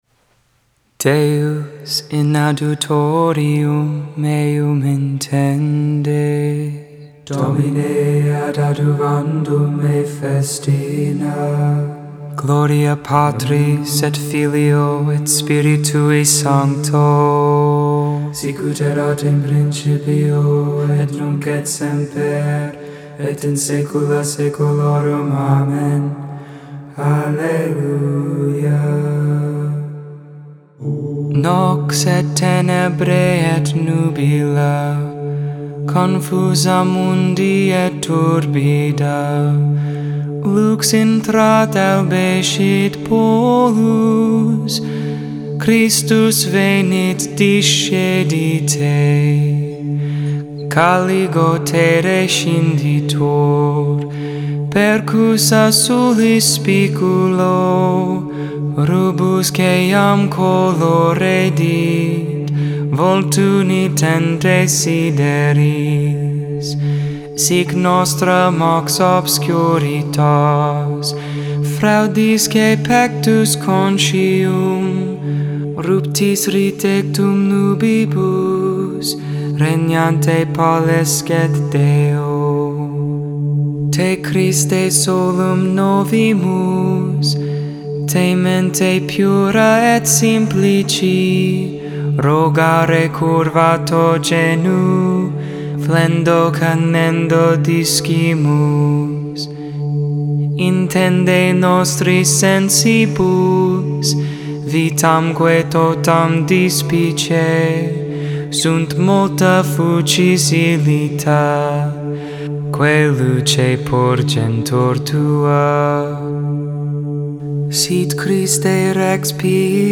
The Liturgy of the Hours: Sing the Hours 1.13.21 Lauds (Wed Morning Prayer) Jan 13 2021 | 00:15:14 1x 00:00 / 00:15:14 Subscribe Share Spotify RSS Feed Share Link Embed